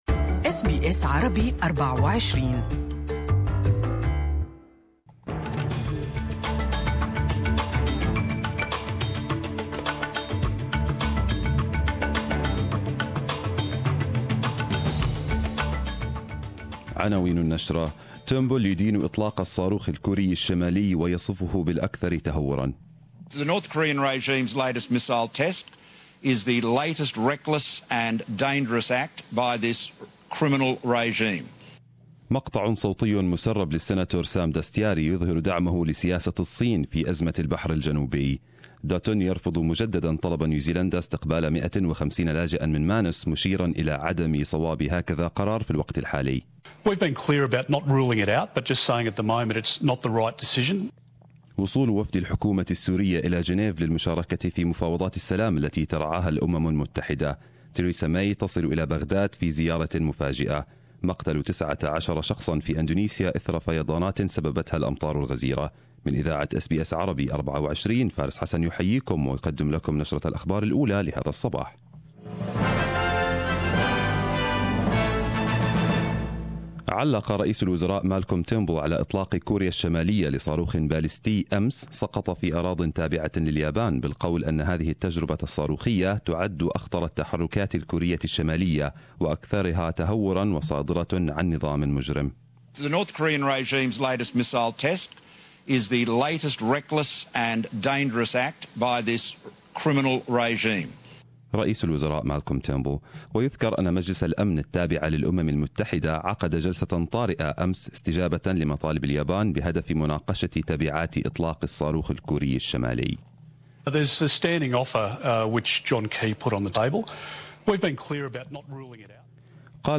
Arabic News Bulletin 30/11/2017